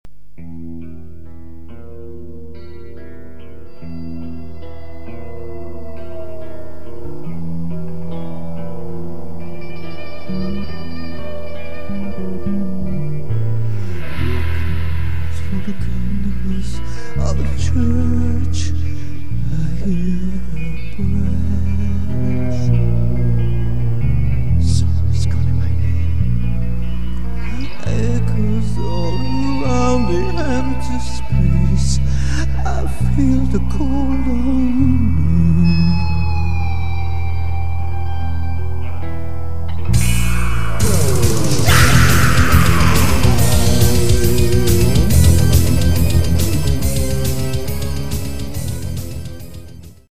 demo
Only my guitar is played for real. Keyboards, Drums, Bass and effects are handled via VST instruments